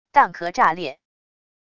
蛋壳炸裂wav音频